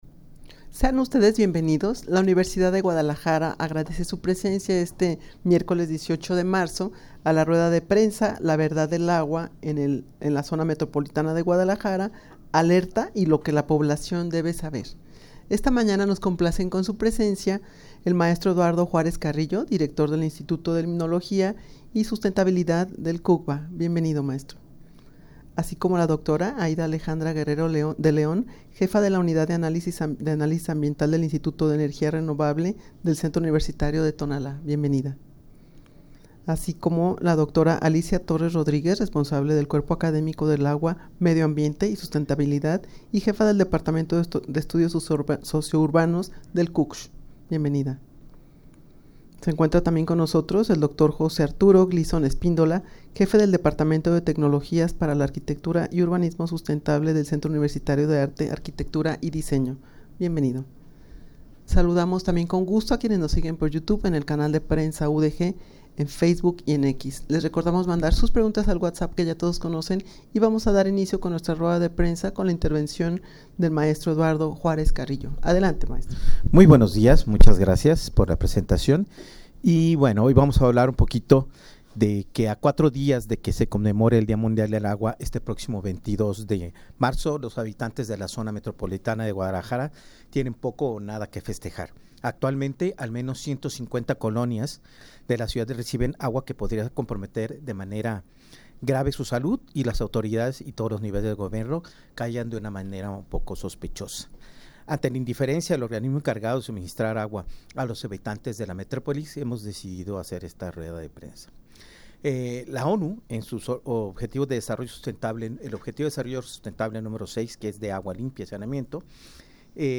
Audio de la Rueda de Prensa
rueda-de-prensa-la-verdad-del-agua-en-el-zmg-alerta-y-lo-que-la-poblacion-debe-saber.mp3